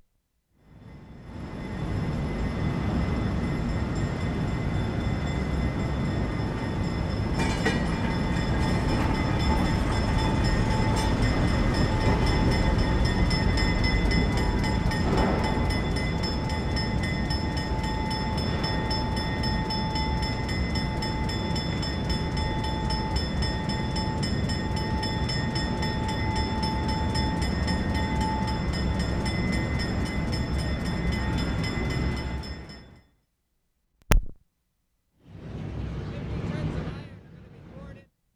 SYDNEY, NOVA SCOTIA Oct. 15, 1973
DIESEL COMING IN 0'30"
6. Bell ringing and a generally loud hum.